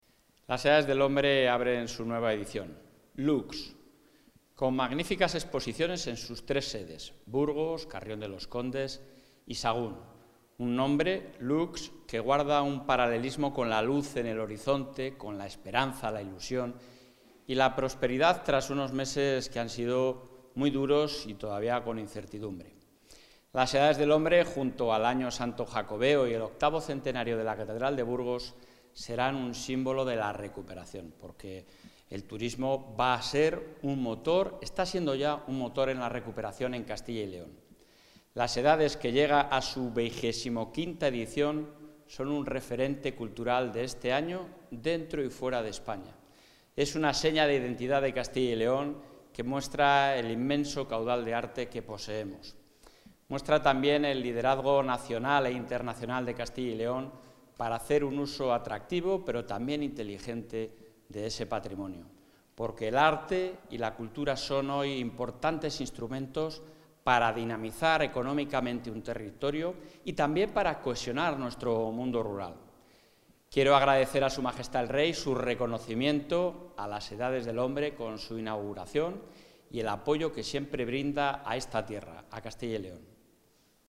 Mensaje del presidente de la Junta.